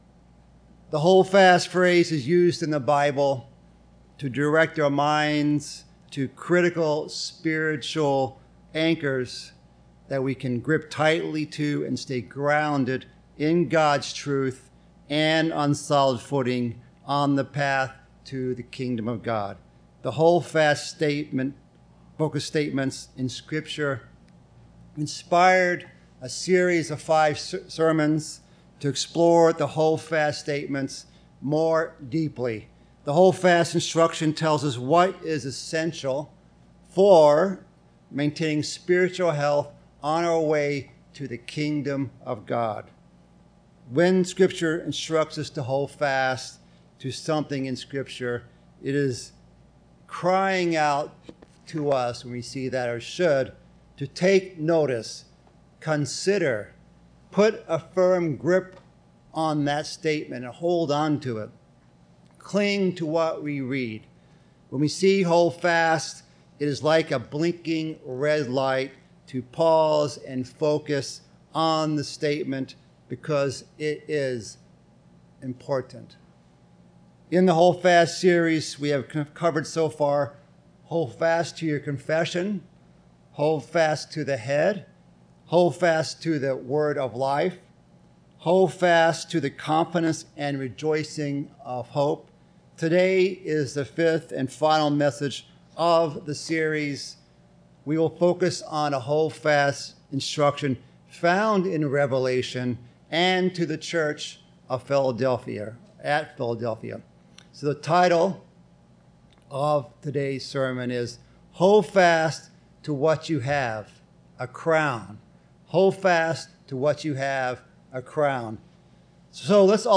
The hold-fast focus statements in scripture inspired a series of 5 sermons to explore the hold-fast statements more deeply.